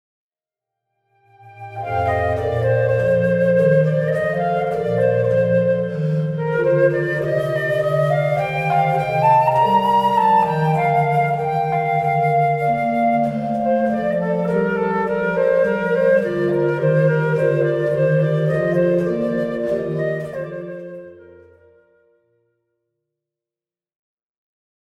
en mi bémol majeur-Affectuoso